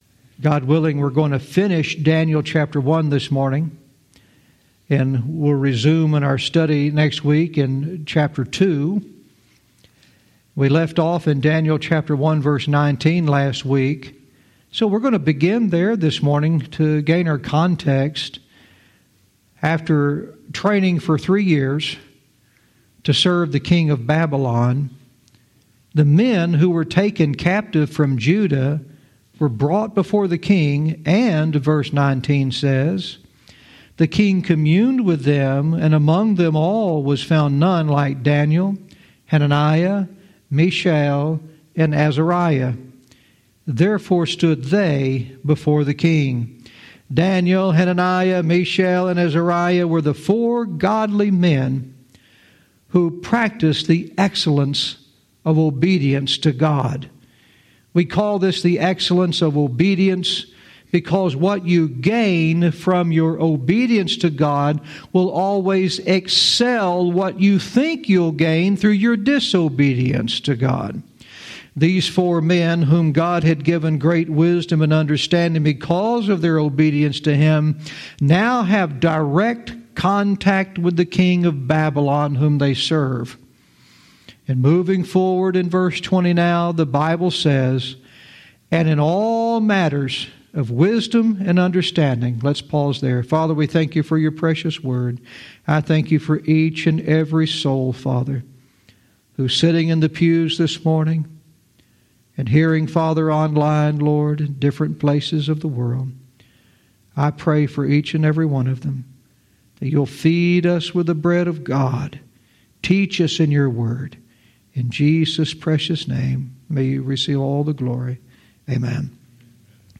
Verse by verse teaching - Daniel 1:20-21 "Sovereignty in the Chaos"